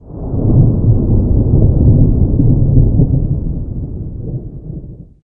thunder35.ogg